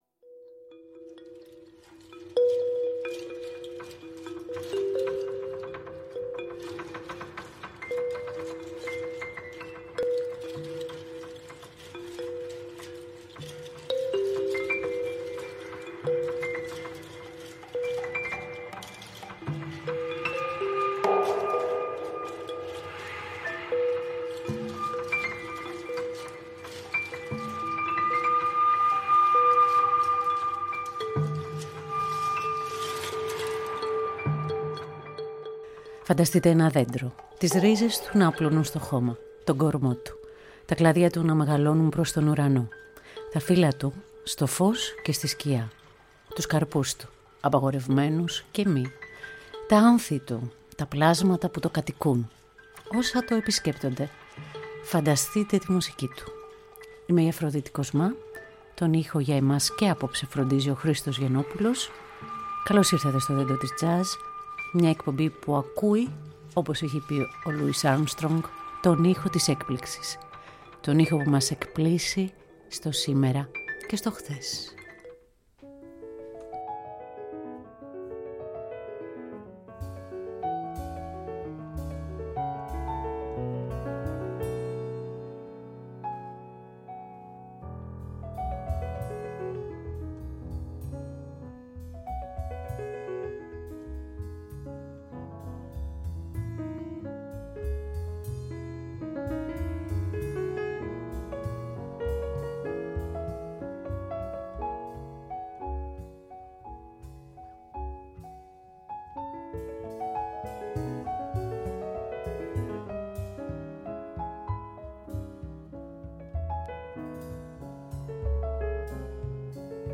Στο Δέντρο της J𝒶𝓏𝓏 Νο. 5 τα κλαδιά του Δέντρου μπλέκουν με έργα της Κλασικής Μουσικής.
Ένα από τα κλαδιά της σύγχρονης ηλεκτρονικής τζαζ ανήκει στη Νορβηγική σκηνή.
Μουσική Τζαζ